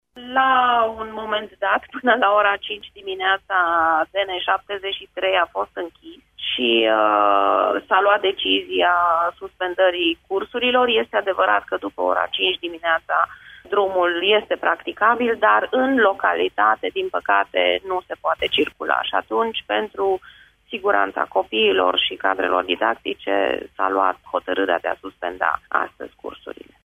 Inspectorul școlar general al Inspectoratului Școlar Județean Brașov, Ariana Bucur: